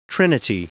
Prononciation du mot trinity en anglais (fichier audio)